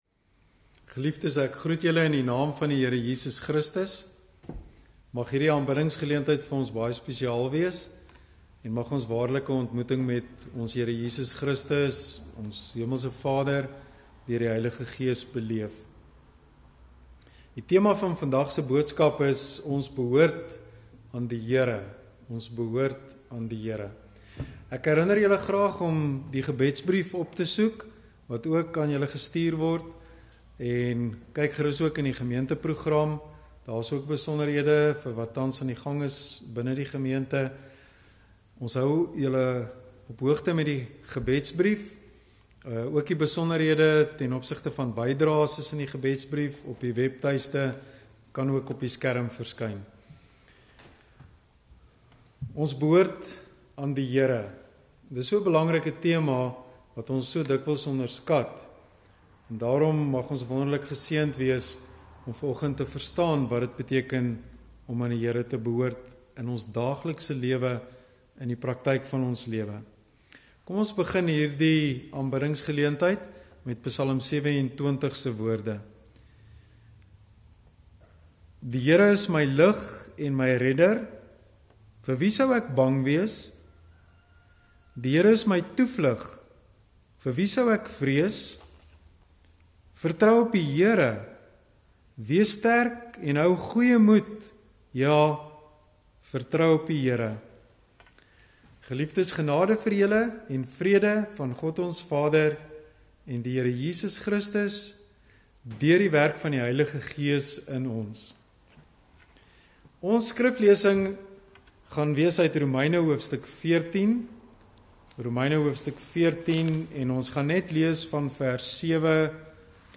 Erediens - 28 Junie 2020
Indien jy te min data het om die erediens in video-formaat te kyk, hier is die diens in mp3 formaat.